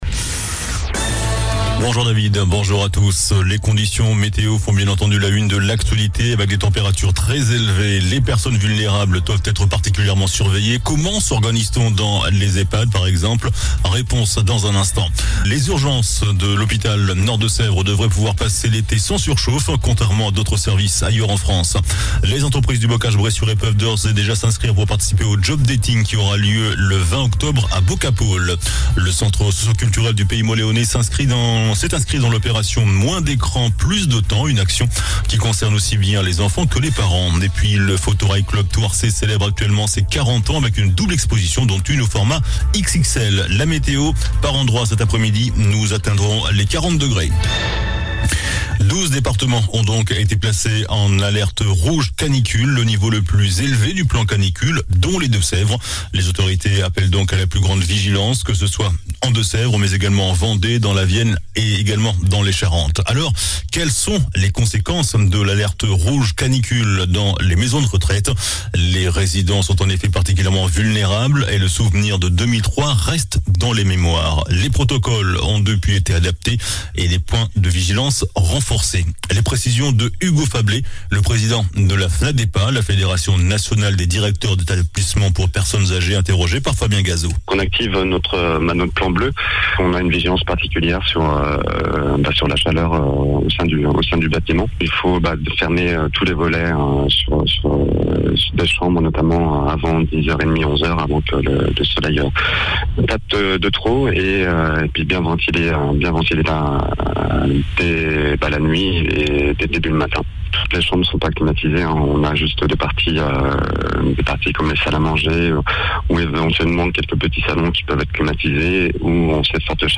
JOURNAL DU VENDREDI 17 JUIN ( MIDI )